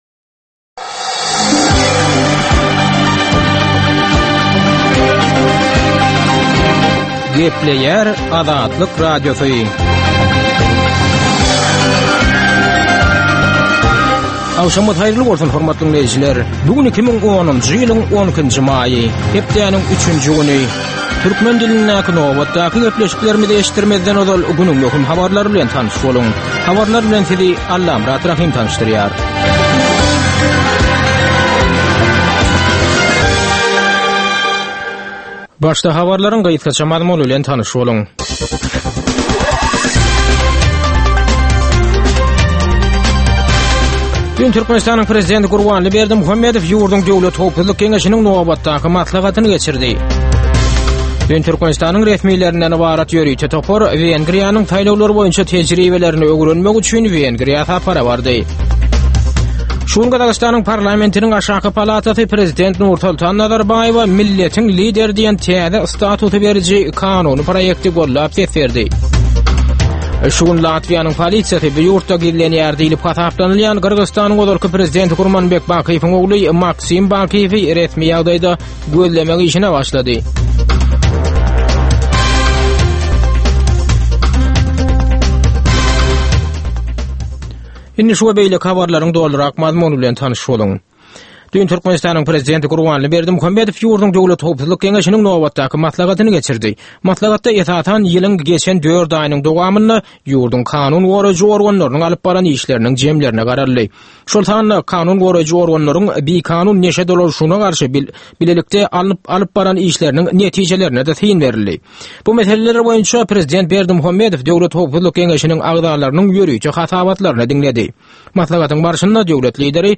Dünýäniň dürli regionlarynda şu günki bolan we bolup duran soňky wakalar barada gysgaça täzelik habarlary.